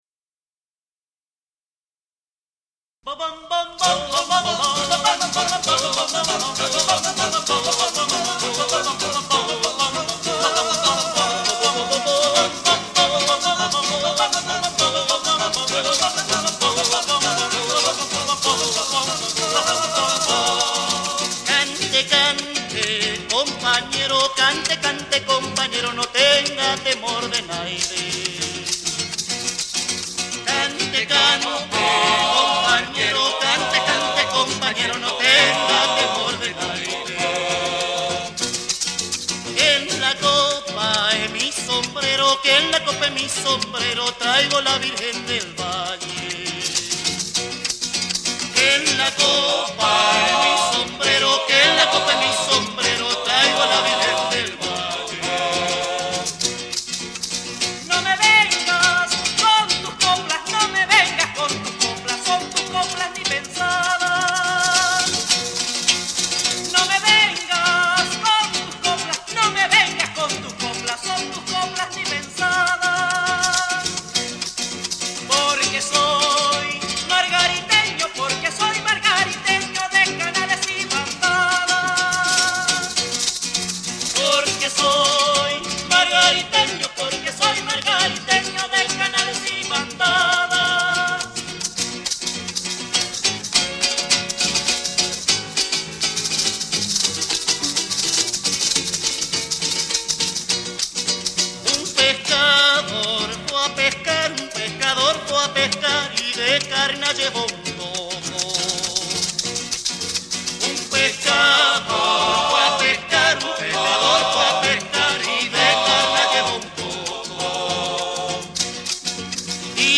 3'15" Polo.